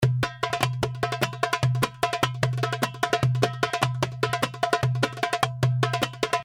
150 BPM malfuf and baladi Darbuka (16 variations)
This package contains real darbuka loops in baladi rhythm, playing at 150 bpm.
The darbuka was recorded with vintage neumann u87 in a dry room by a professional Darbuka player. The darbuka you are hearing and downloading is in stereo mode, that means that The darbuka was recorded twice.(beat in the left speaker).
There is only light and perfect analog EQ and light compression, giving you the The opportunity to shape the loops in the sound you like in your song.